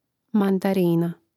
mandarína mandarina